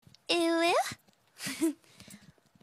Звук уввуу uwu от стримерши